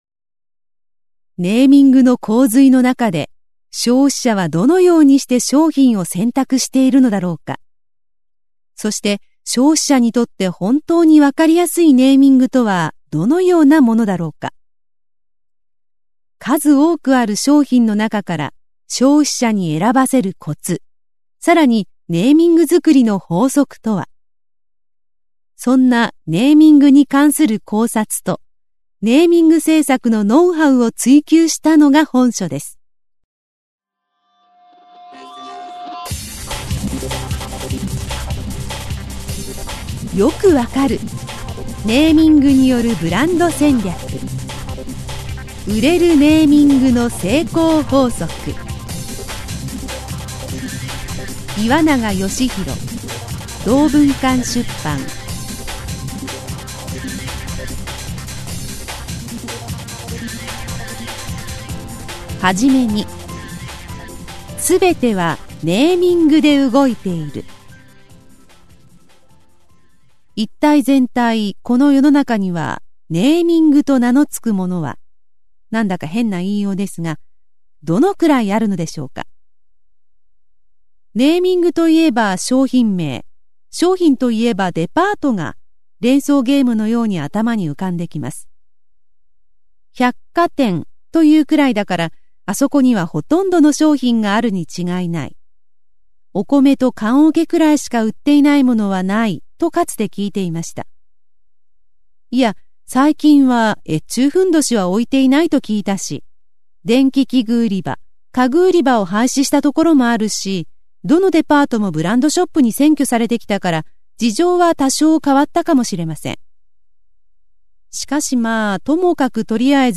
[オーディオブックCD] 「売れるネーミング」の成功法則